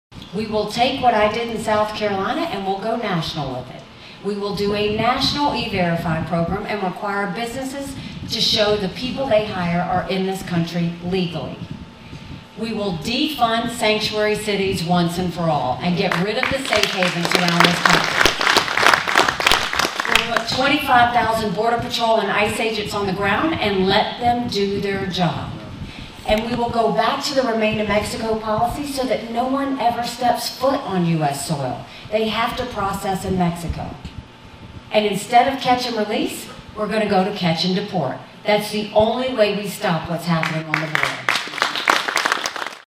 Republican Presidential Candidate Nikki Haley Makes Campaign Stop in Atlantic